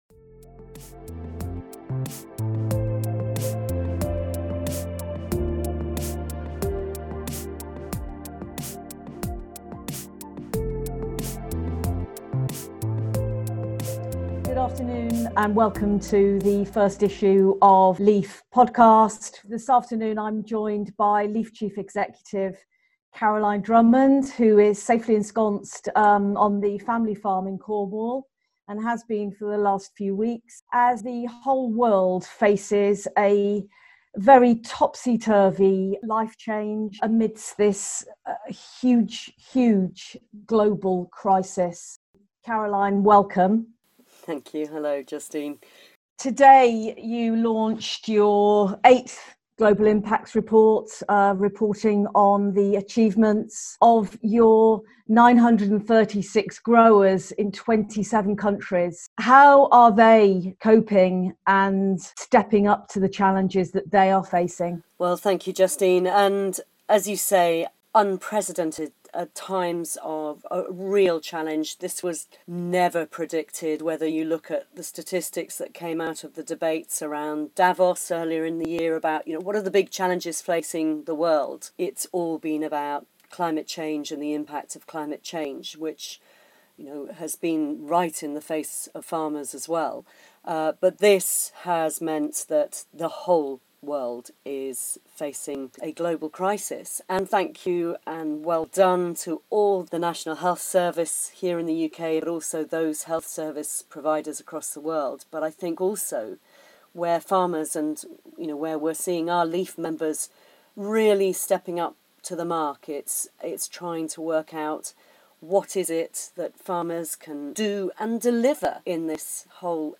LEAF Podcast: Interview